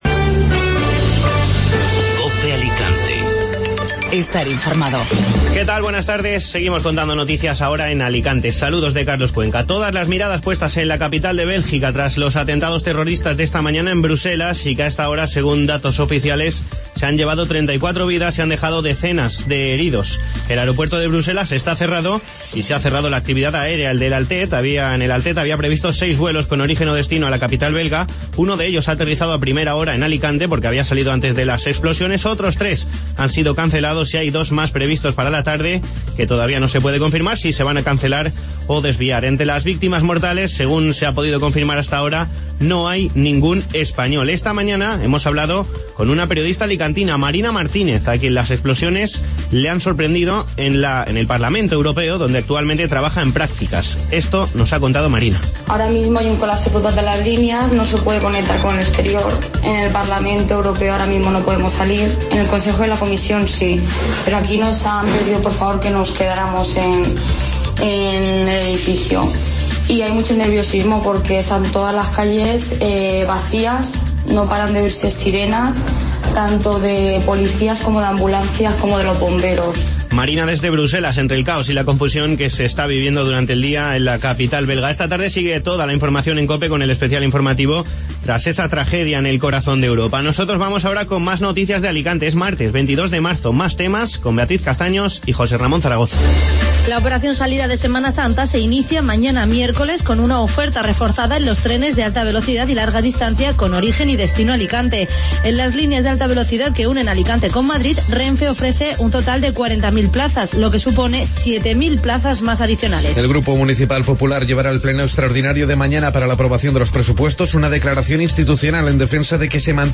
Hablamos con una periodista alicantina residente en Bruselas. Nos cuenta de primera mano cómo se está viviendo el horror de los atentados terroristas.